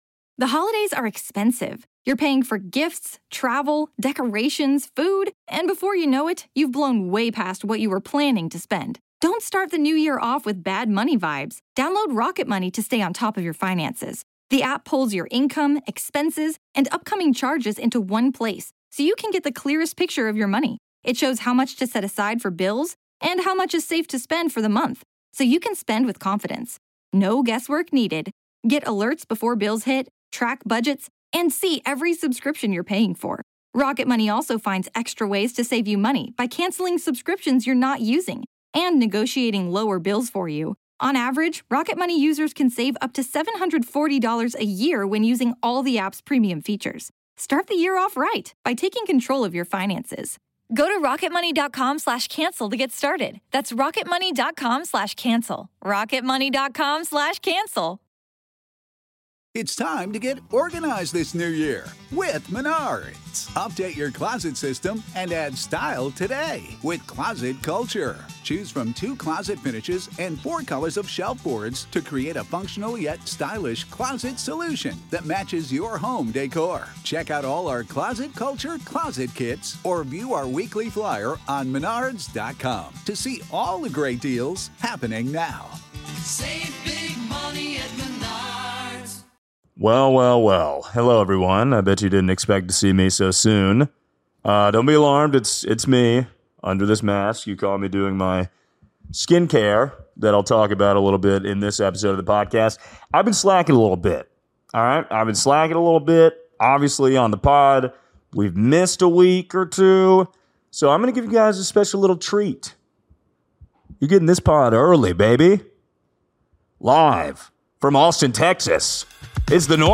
Live From Austin, Texas!